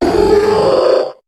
Cri de Séléroc dans Pokémon HOME.